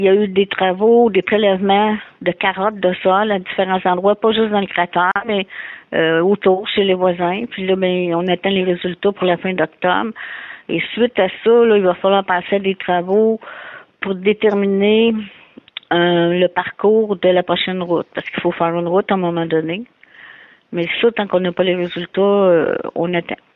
Mme Gendron a fait savoir qu’il faudra encore être patient pour connaître la nouvelle route qui sera dans ce secteur.